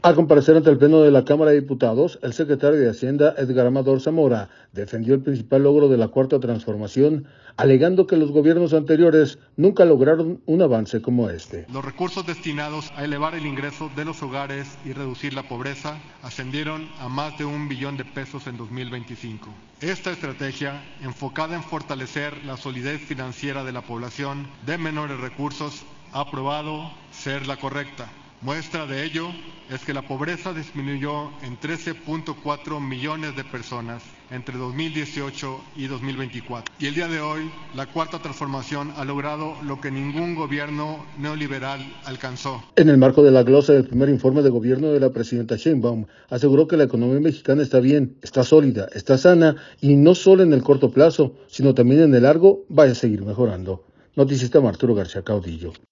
Secretario de Hacienda comparece ante diputados